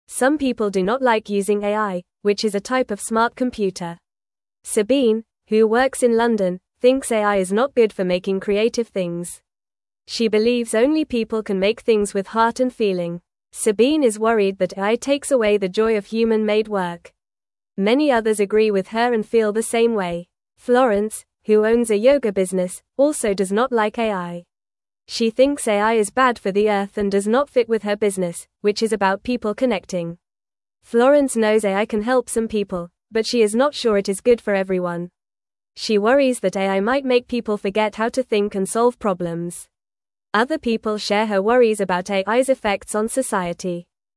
Fast
English-Newsroom-Beginner-FAST-Reading-People-Worry-About-AI-and-Creativity-and-Connection.mp3